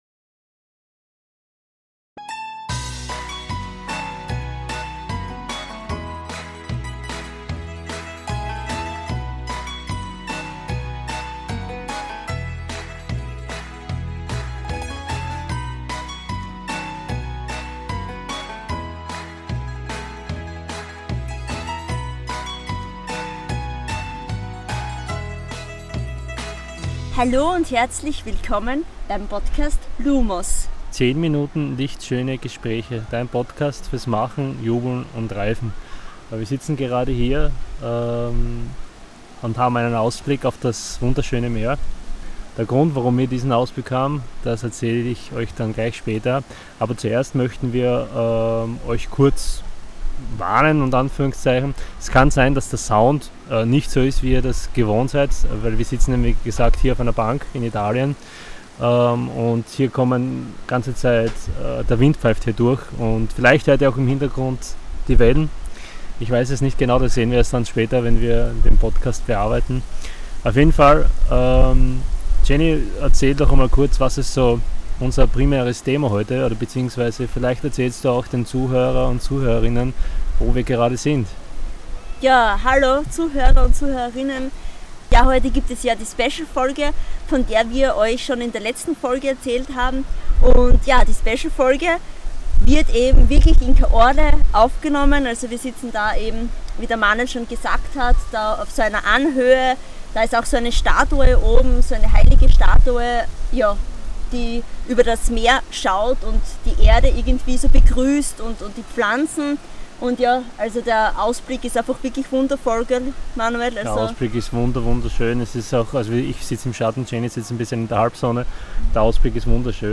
Dich erwartet wieder eine authentische, kurze, knackige Folge… bei der du nicht nur das Meer im Hintergrund Rauschen hörst, sondern wirklich das Gefühl hast, dass die paar Minuten eine Auszeit für deine Seele sind! Stichwort: Auszeit – wir sprechen darüber wie wichtig es ist, sich immer wieder eine Auszeit zu nehmen & sich vom Strudel des Alltags zu befreien!